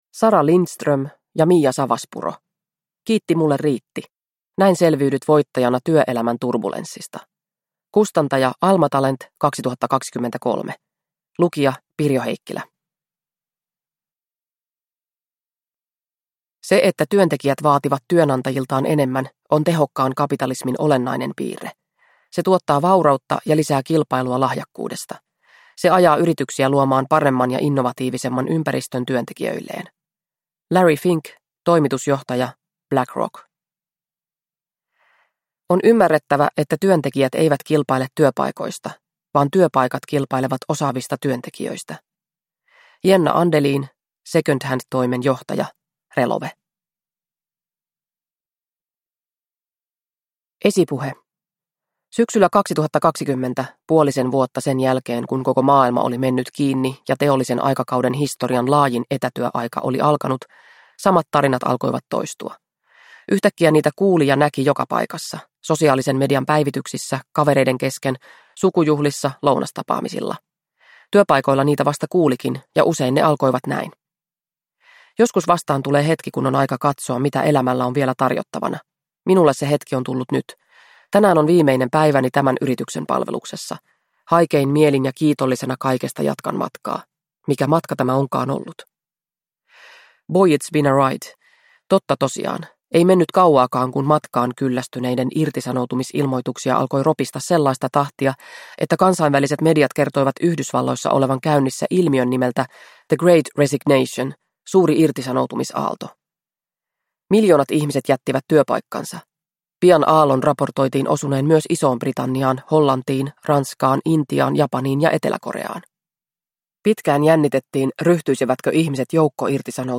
Kiitti mulle riitti! – Ljudbok – Laddas ner